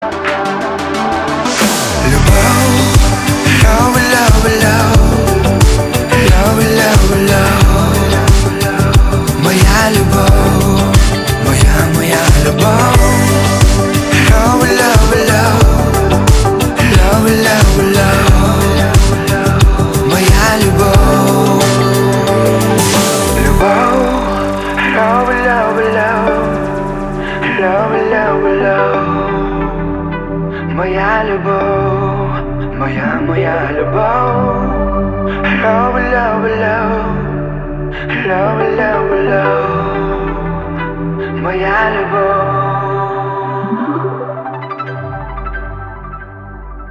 • Качество: 320, Stereo
поп
спокойные
украинские